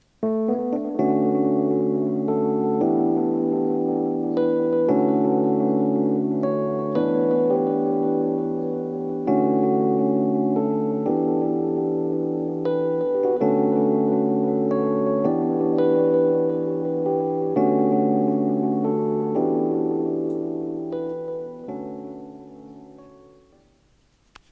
Rhodes very very vintage